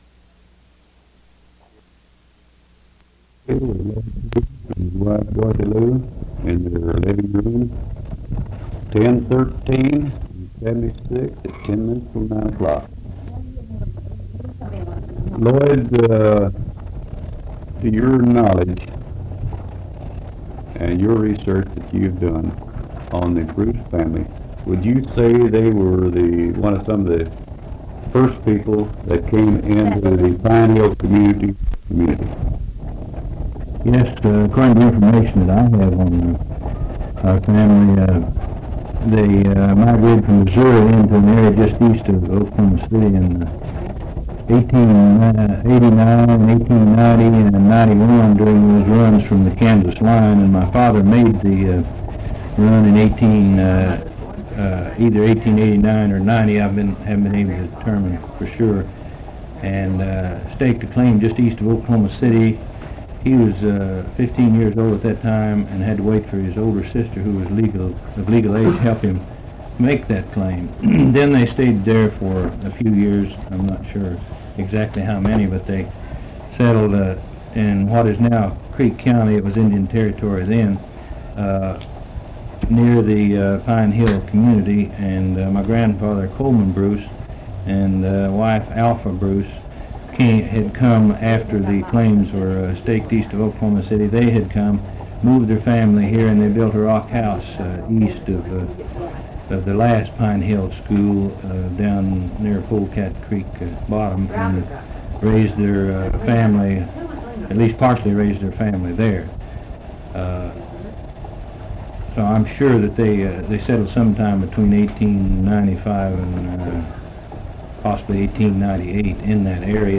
Oral History Archive